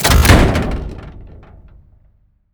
reload1.wav